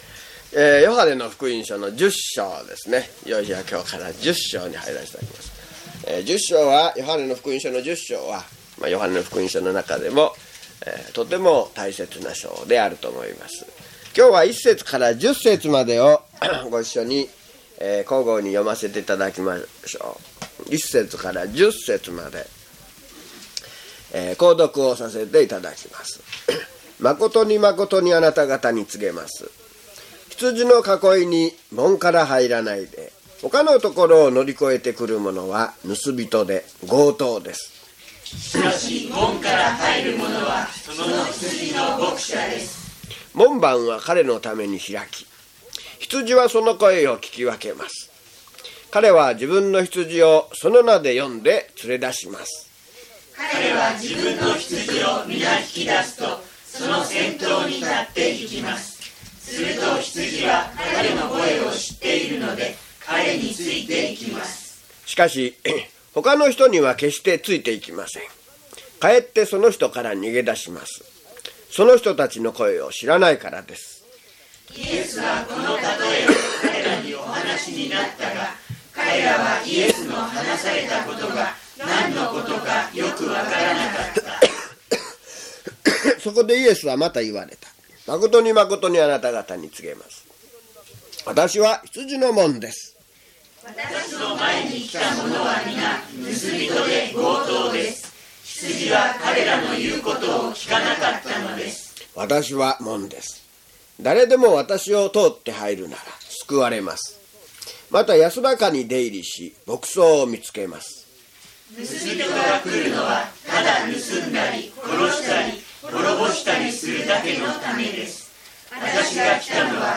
hp_john066mono.mp3